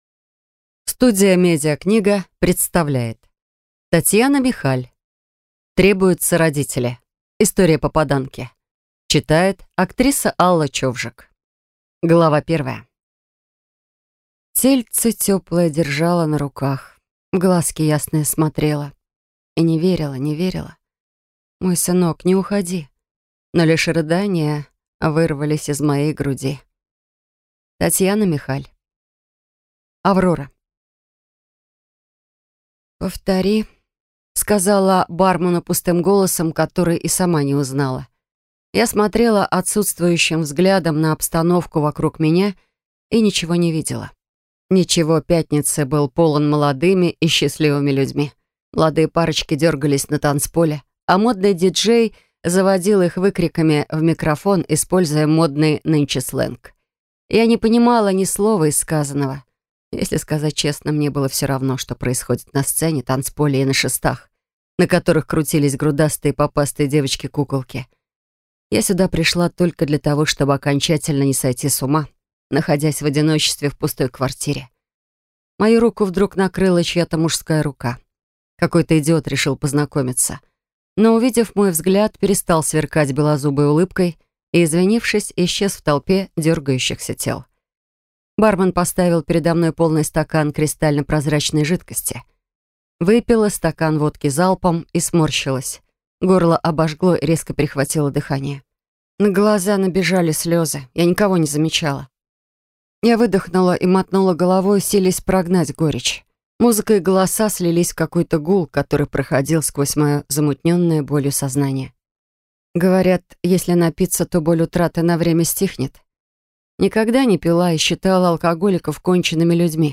Аудиокнига Требуются родители. История попаданки | Библиотека аудиокниг
Прослушать и бесплатно скачать фрагмент аудиокниги